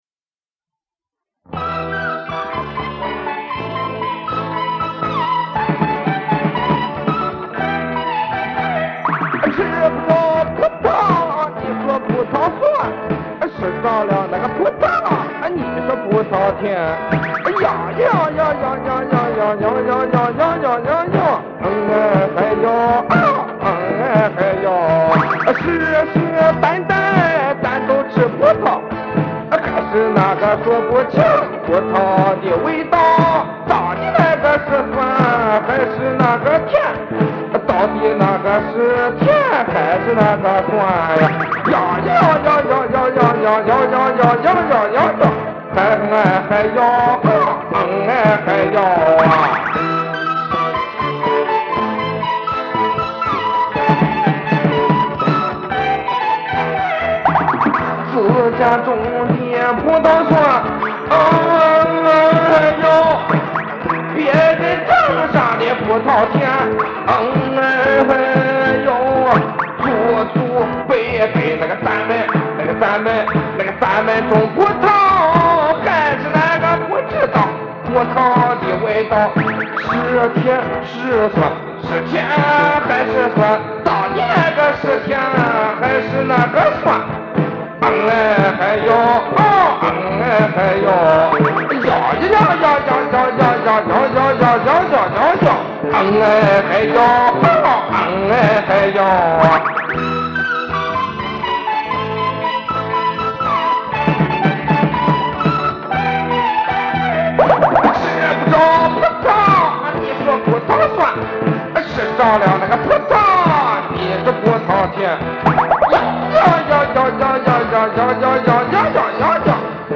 但他的声音不错
只买到了采集的音频，没想到不仅音质差，还是单声道的